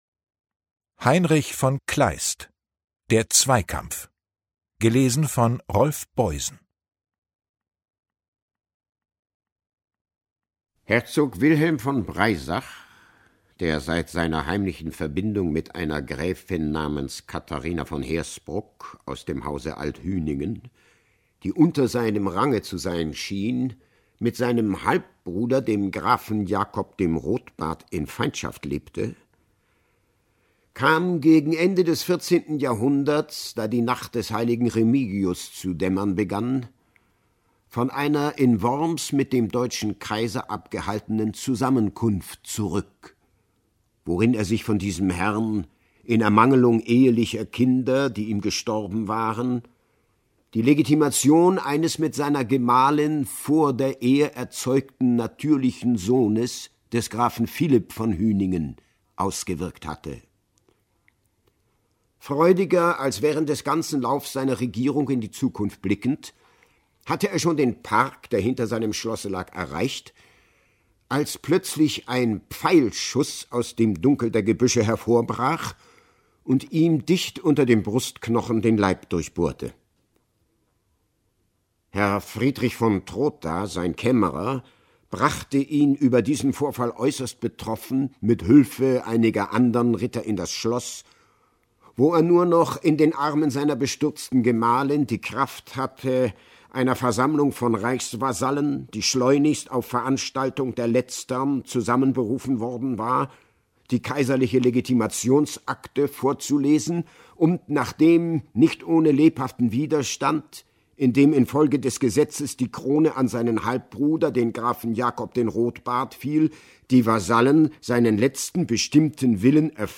Rolf Boysen (Sprecher)
2022 | Ungekürzte Lesung
Rolf Boysen liest die berühmte Kleist-Novelle mit unverwechselbarem Timbre.